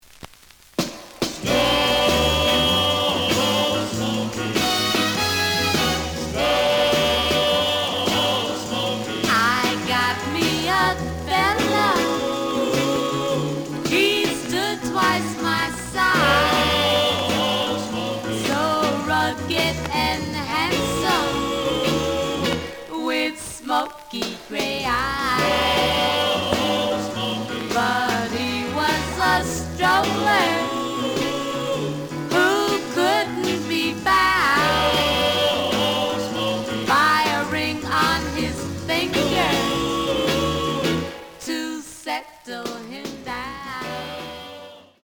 試聴は実際のレコードから録音しています。
●Genre: Rhythm And Blues / Rock 'n' Roll
盤に若干の歪み。